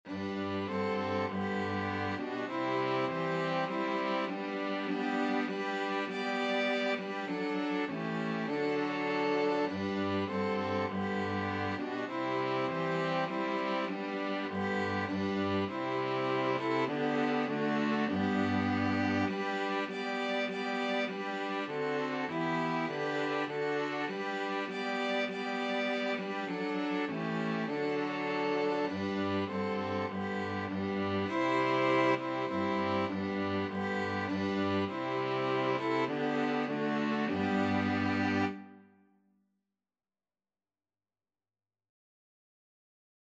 Christian
Violin 1Violin 2ViolaCello
4/4 (View more 4/4 Music)
String Quartet  (View more Easy String Quartet Music)
Classical (View more Classical String Quartet Music)